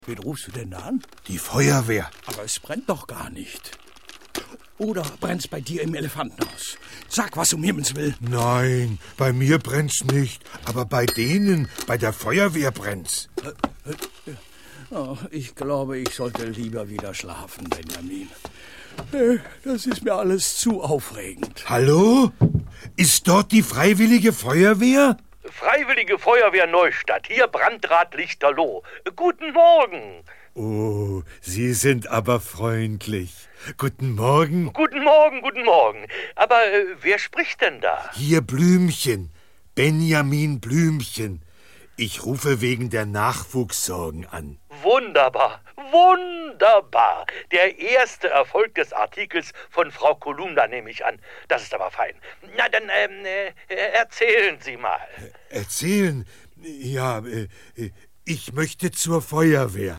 Ravensburger Benjamin Blümchen - ...als Feuerwehrmann ✔ tiptoi® Hörbuch ab 3 Jahren ✔ Jetzt online herunterladen!